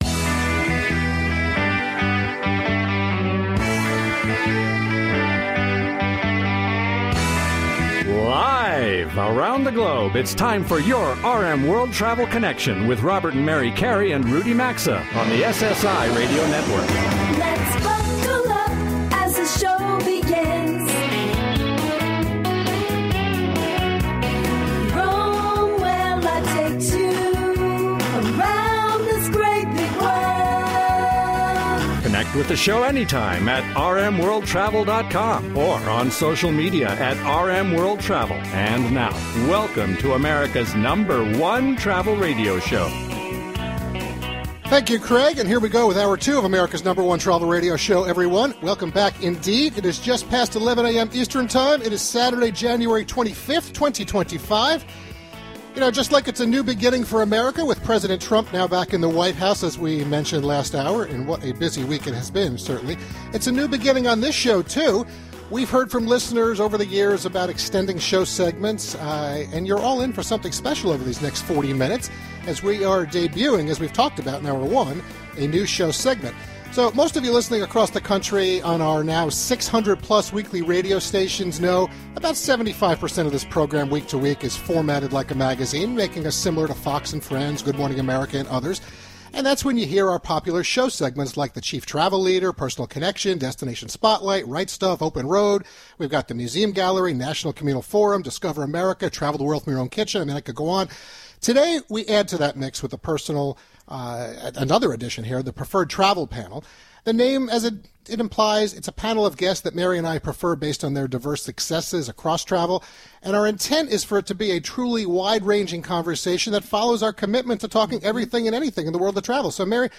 America's #1 Travel Radio Show.
They provide straight-forward advice and commentary, inside scoop, tips/trends and more, as they connect with the audience and skillfully cover the world of travel, culture, and its allure through modern segments, on-location remote broadcasts, on-air showcasing and lively banter.